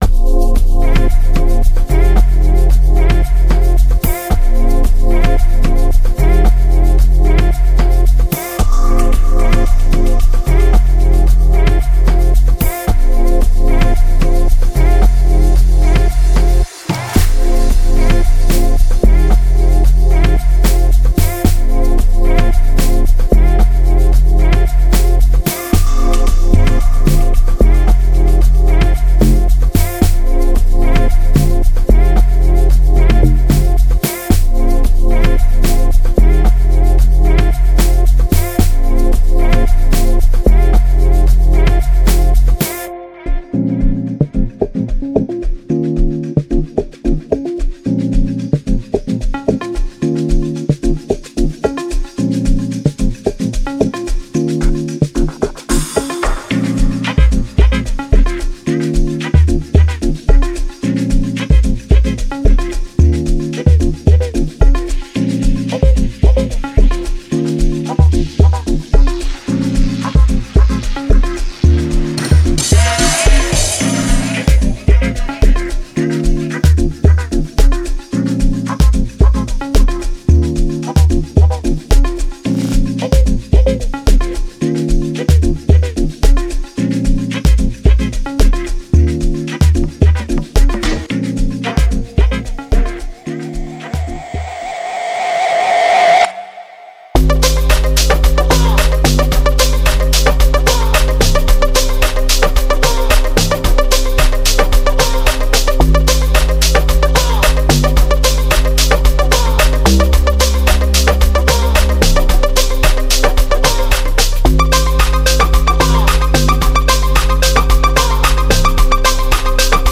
Genre:Amapiano
110から112のテンポで再生されるループは、ジャンルの魅力を引き出す絶妙なテンポで、期待通りの高品質を誇ります。
デモサウンドはコチラ↓